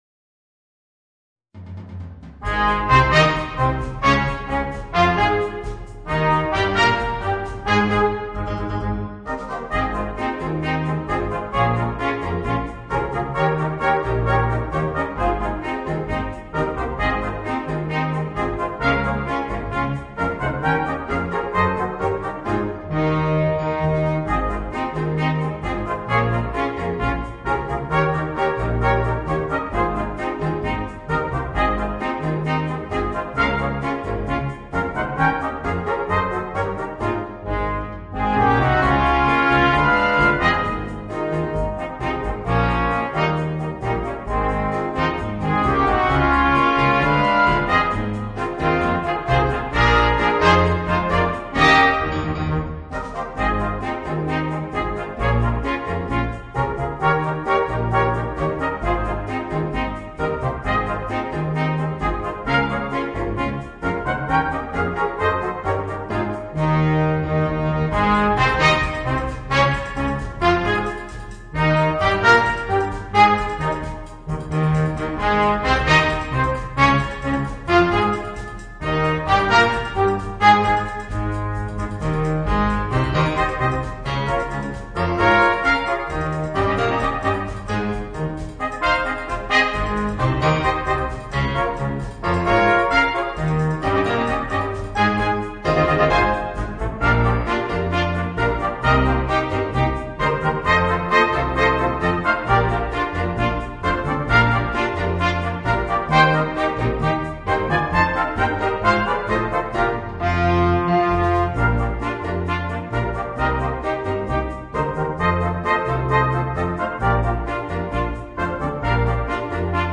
Voicing: 3 Trumpets and 2 Trombones